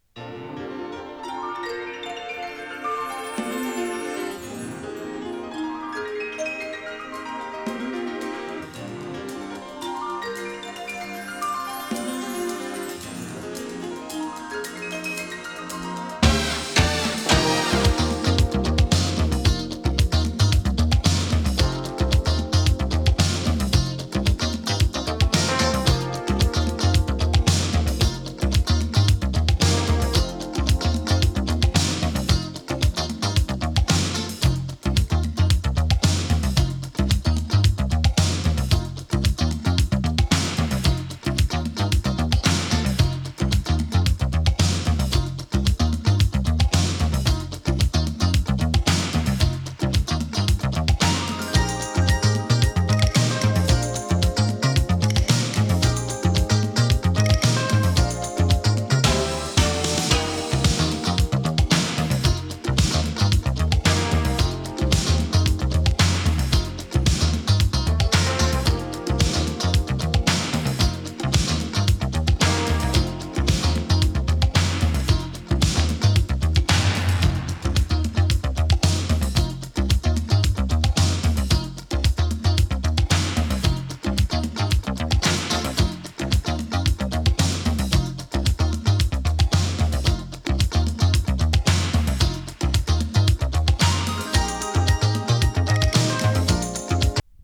Extended Remix 5.28